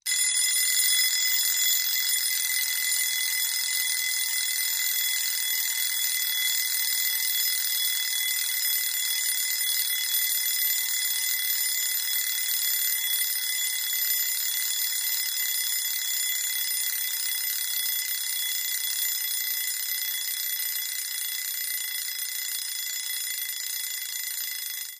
Alarm Clock, Wind - Up, ( 1 ) Low Pitched, Buzzy, Fast Ringing.